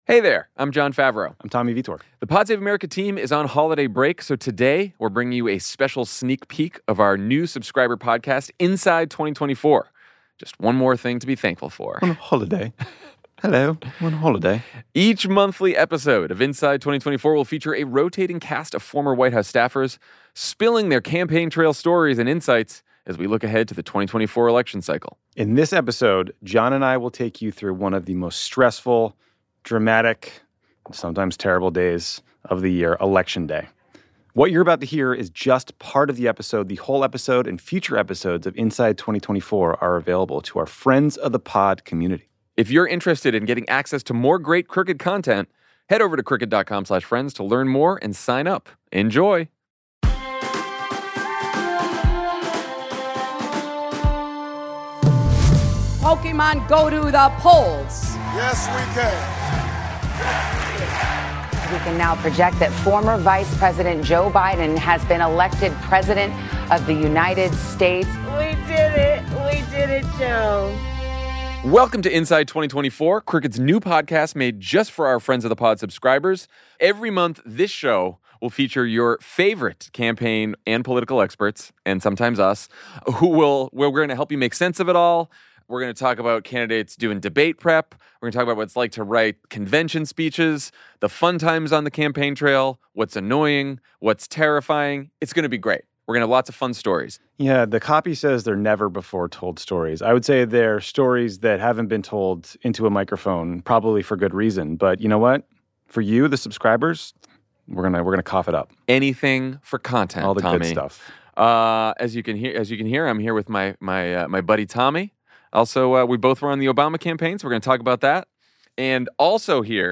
In this preview Jon Favreau and Tommy Vietor take you behind the scenes of election nights like Barack Obama’s 2008 winning campaign.